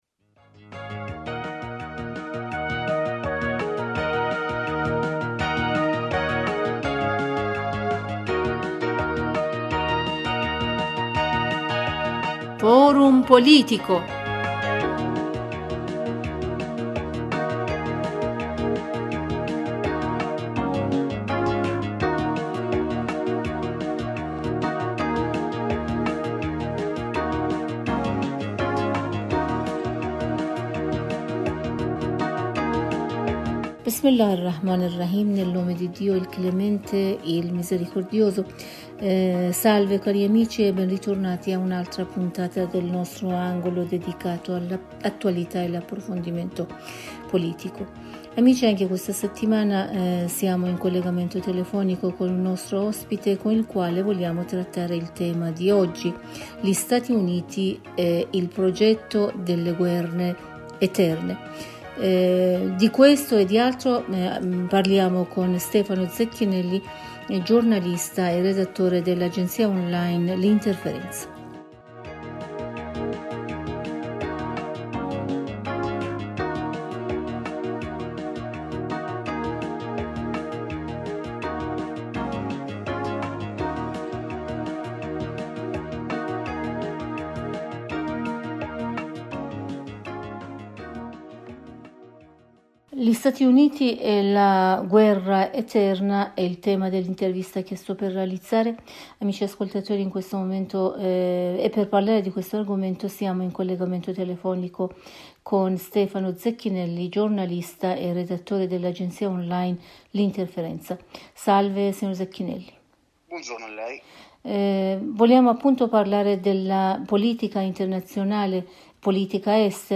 e' stato intervistato dalla nostra Redazione.